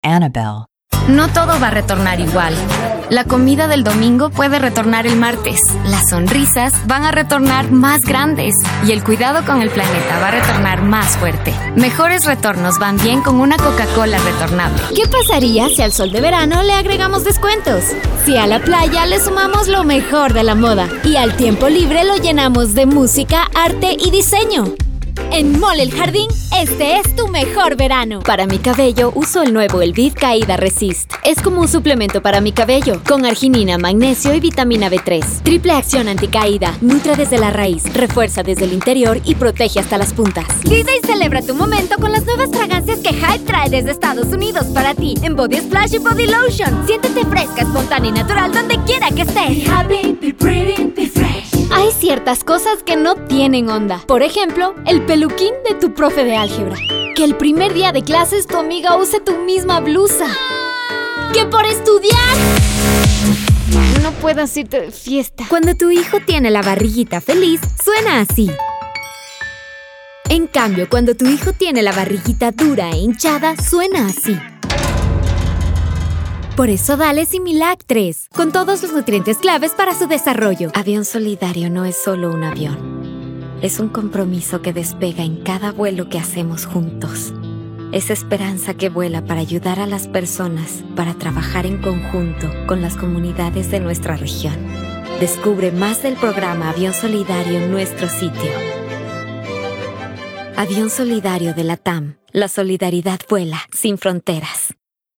Voice Talent